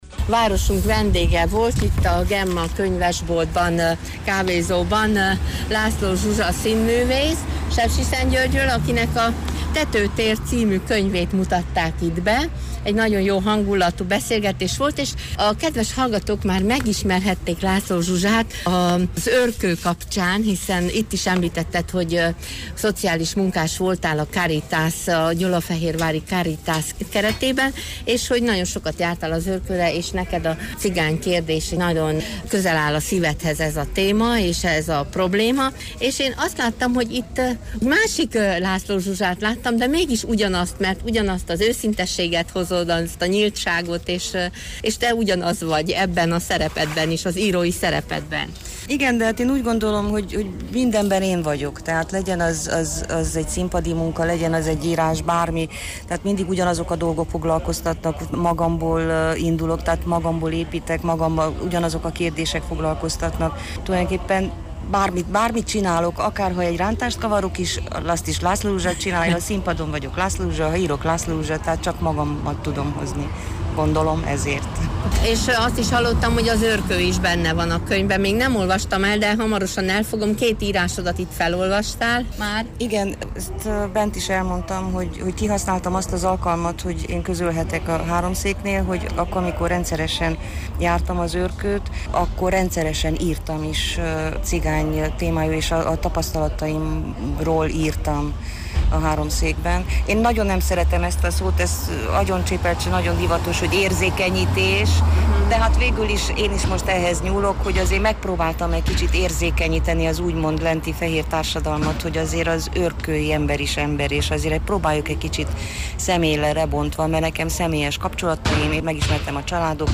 A Gemma irodalmi kávézóban találkoztunk vele, és a dedikációk után, a kávézó teraszán szakított ránk néhány percet ismét.